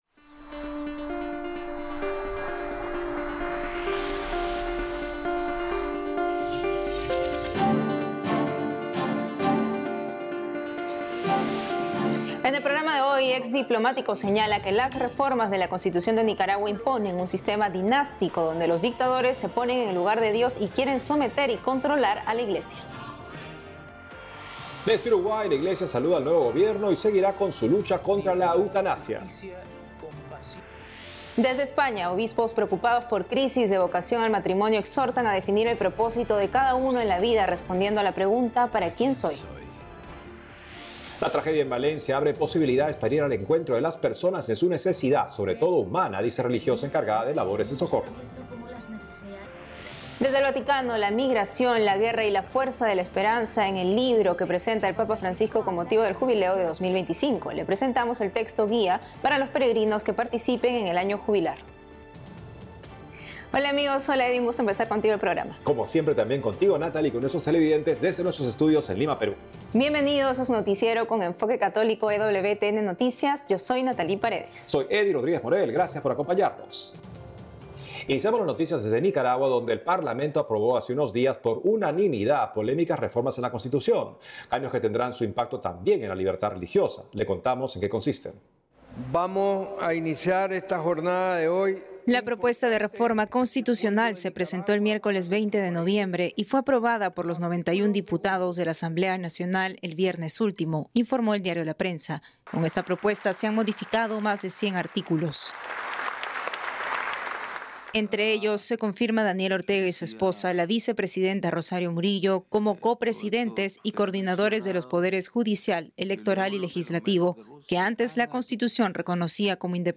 Noticiero diario producido exclusivamente para EWTN por la agencia ACI Prensa de Perú. Este programa informativo de media hora de duración se emite los sábados (con repeticiones durante la semana) y aborda noticias católicas del mundo y las actividades de Su Santidad Francisco; incluye también reportajes a destacados católicos de América del Sur y América Central.